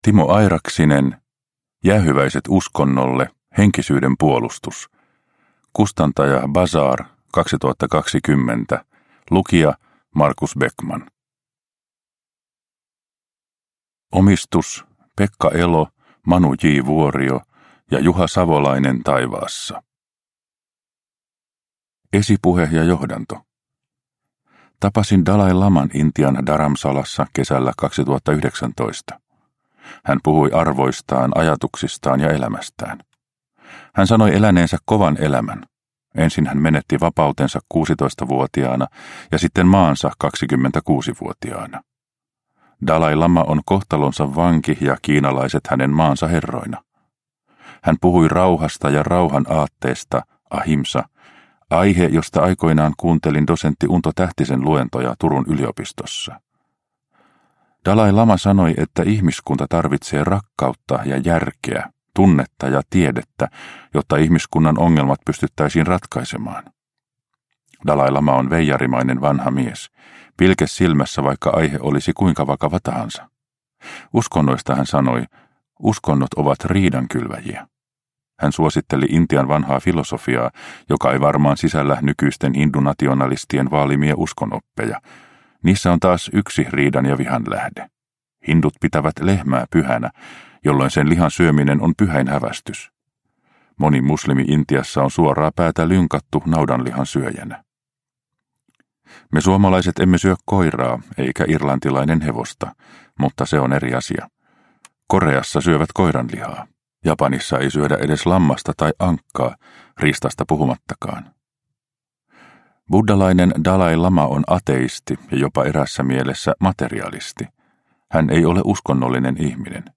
Jäähyväiset uskonnolle – Ljudbok – Laddas ner